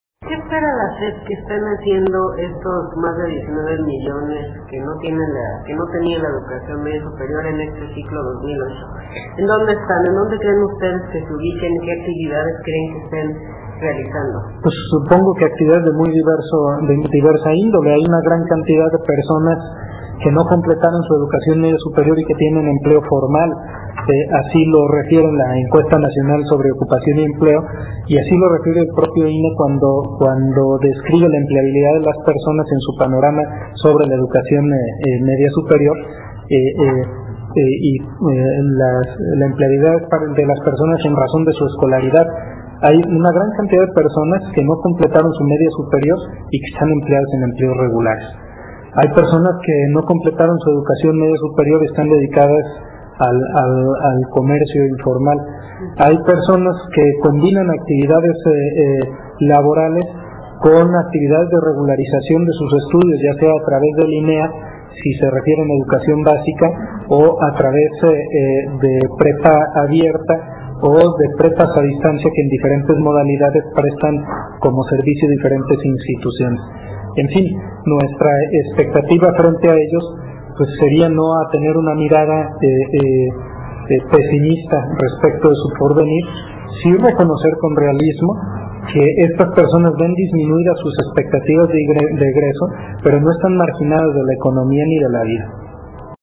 Se anexa el audio de la respuesta del Subsecretario de Educación Media Superior...